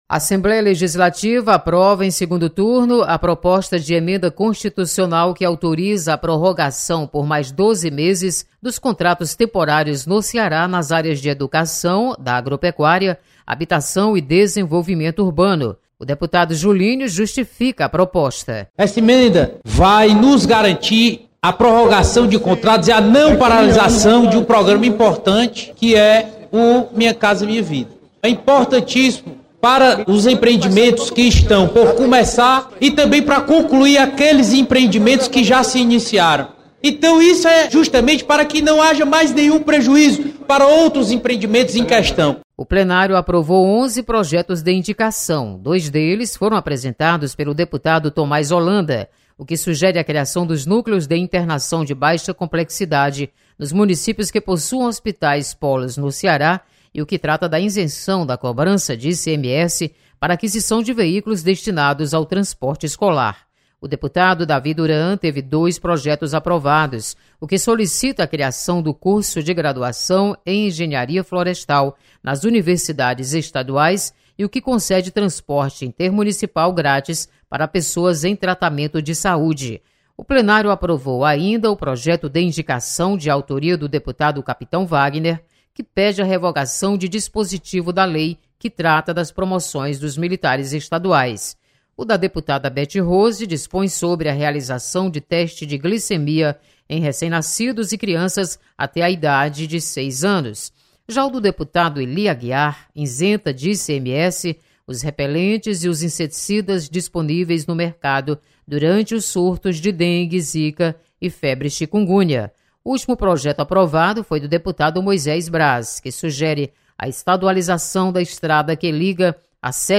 Aprovados contratos temporários no Ceará. Repórter